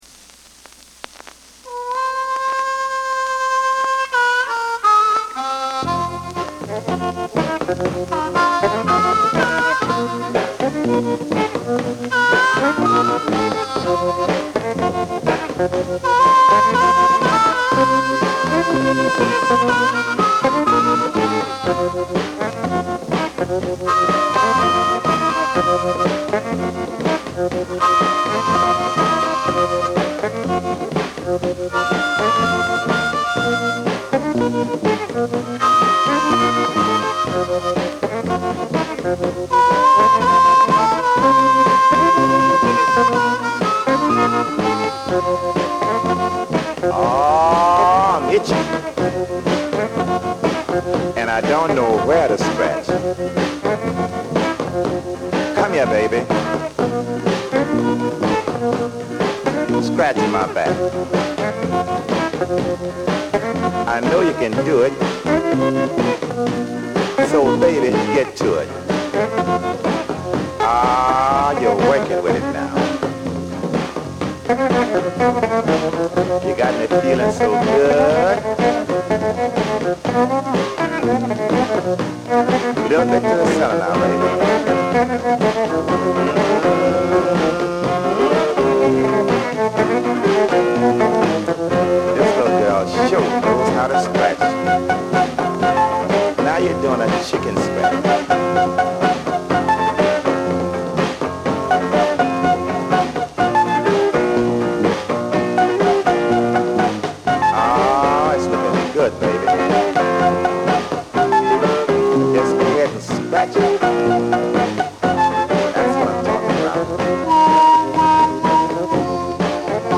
リズム・パート、ブルース・ハープ、そして語り口調のヴォーカル・スタイル。